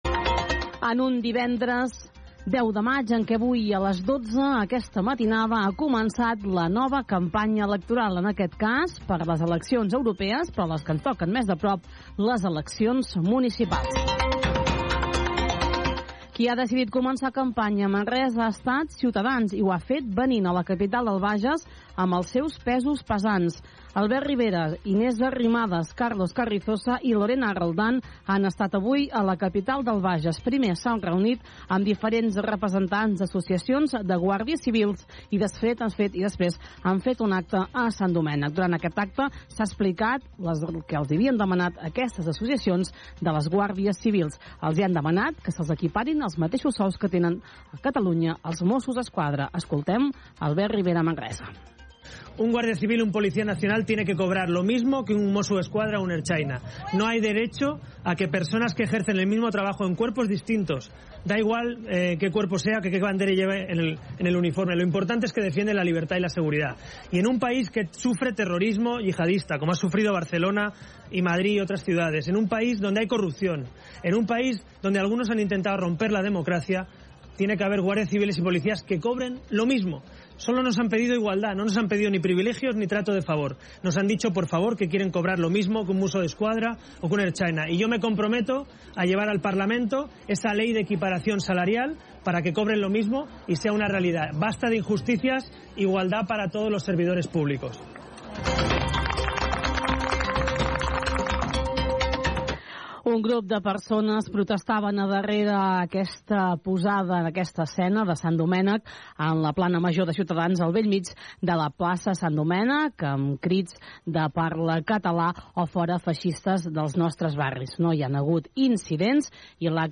Inici de la campanya electoral a les eleccions europees i municipals. Declracions d'Albert Ribera del partit Ciudadanos, a Manresa
Informatiu